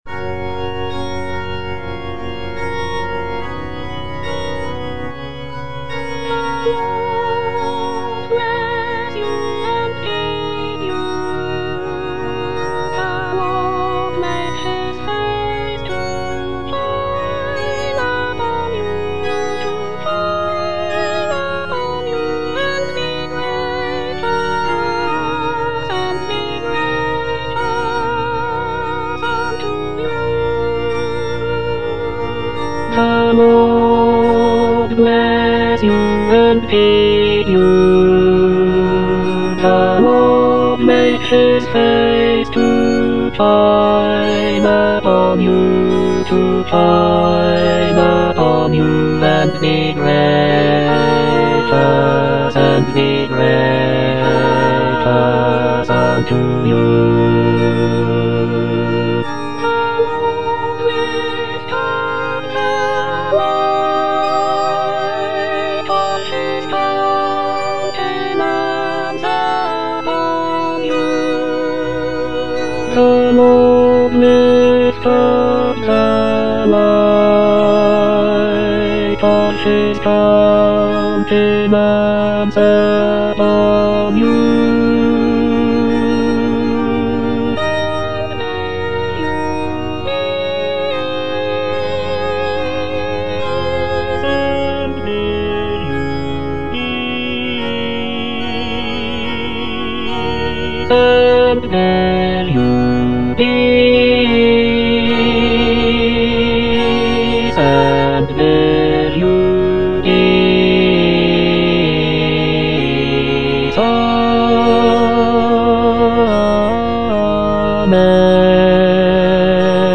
Bass (Emphasised voice and other voices)
is a choral benediction